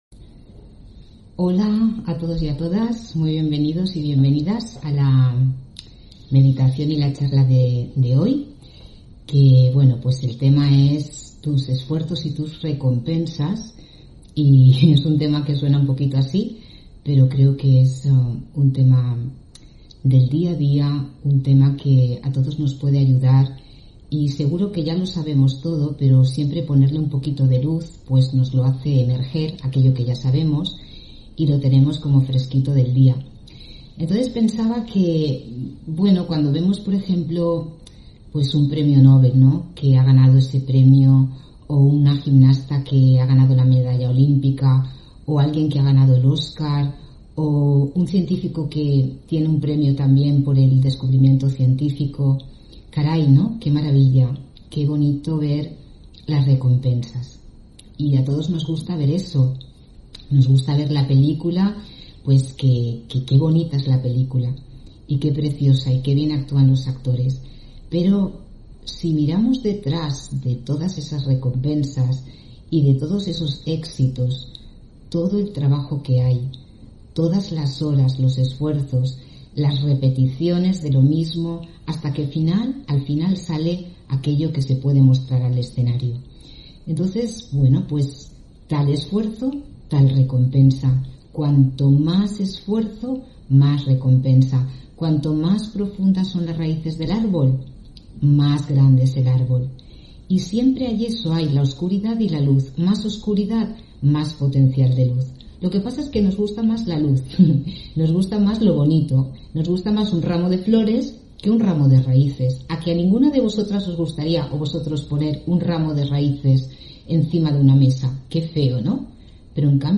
Meditación y conferencia: Tus esfuerzos y tus recompensas (20 Noviembre 2021)